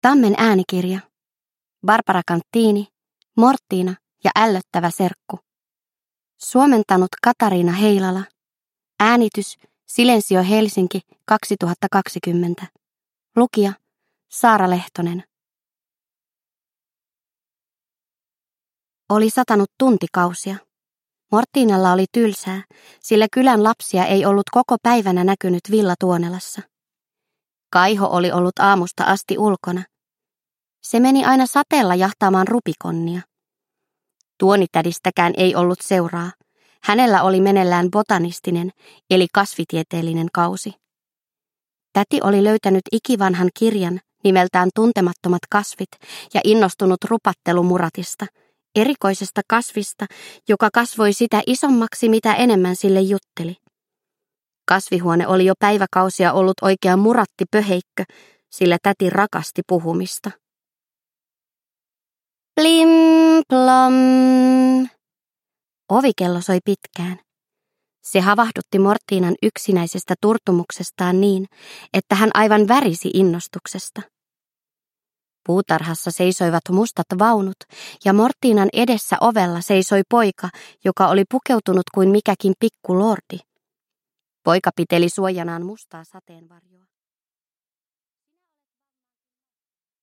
Mortina ja ällöttävä serkku – Ljudbok – Laddas ner